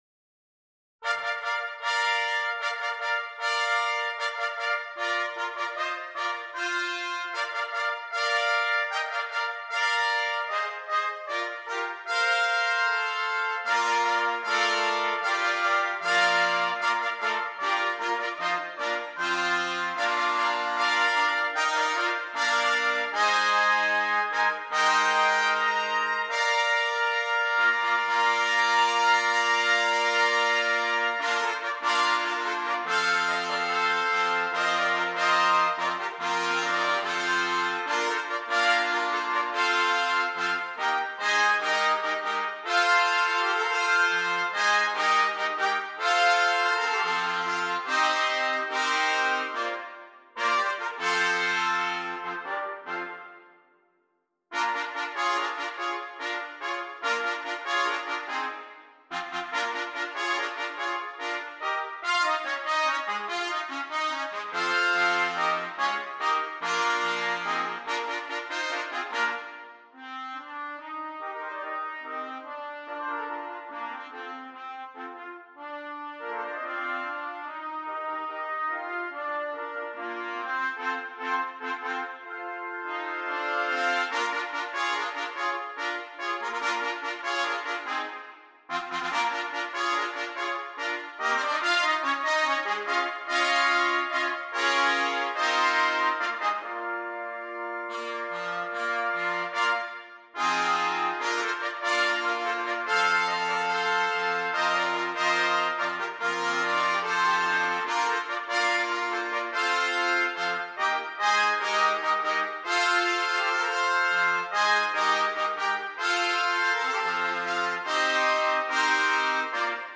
6 Trumpets
a short fanfare/overture